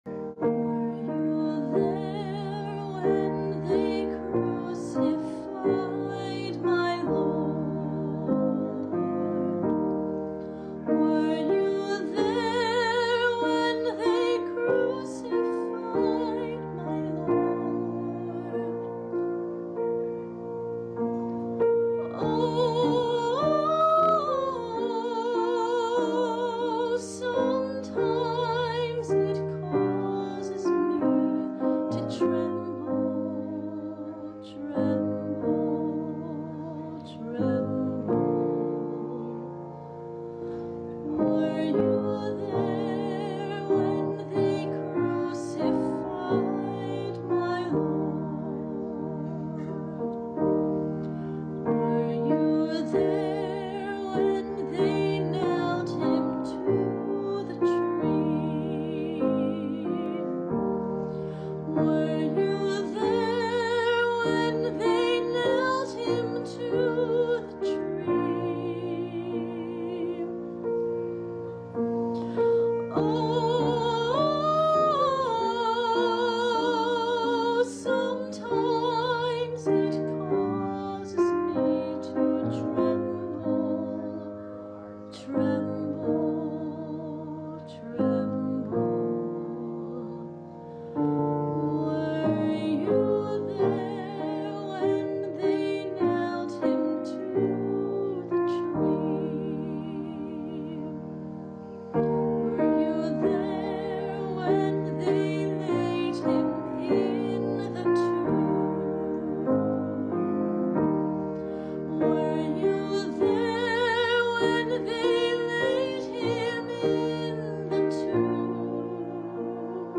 Archbishop Wood: Easter Retreat Mass
Sermon or written equivalent